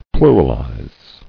[plu·ral·ize]